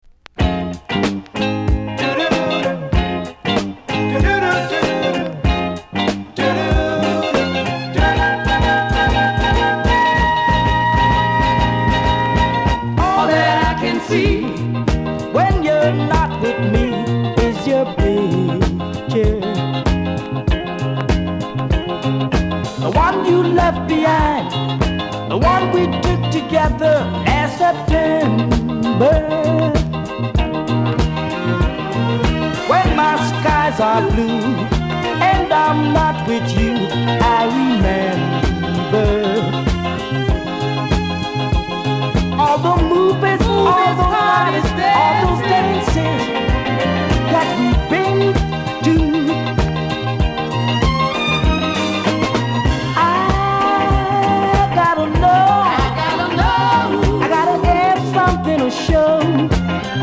※小さなチリノイズが少しあります。
ISLAND DISCO CLASSIC!!